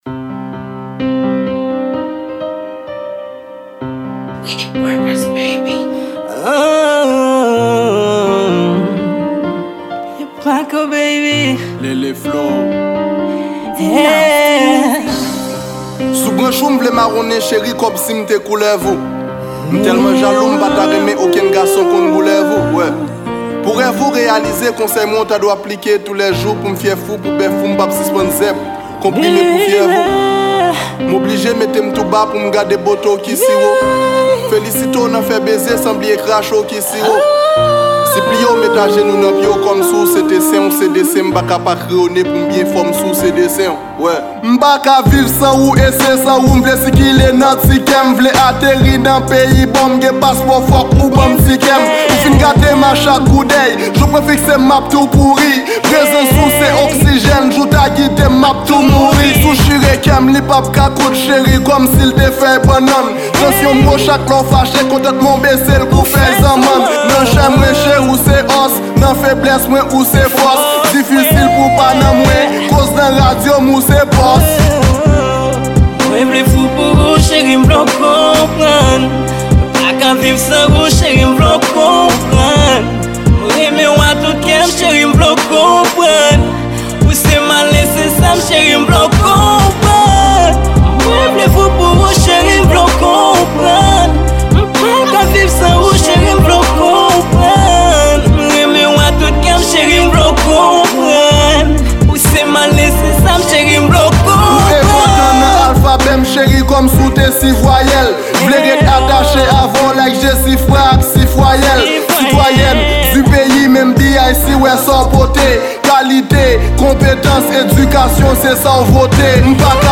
Genr: Slam